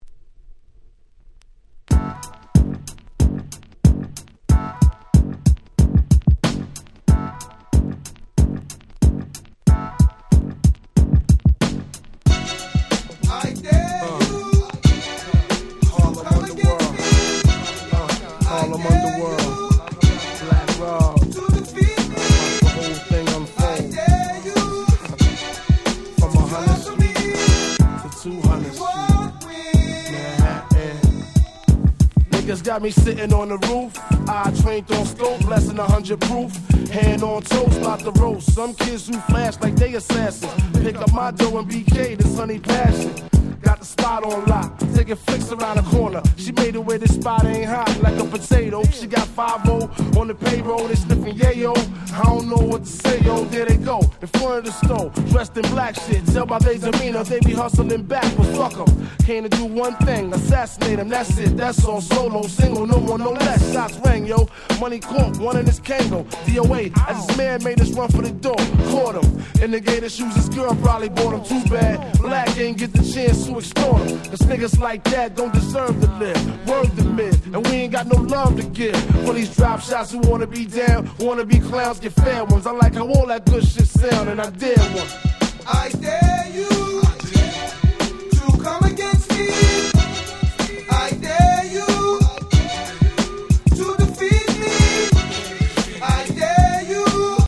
DJ用にイントロにBreakがついて繋ぎ易くなってたりするアレです。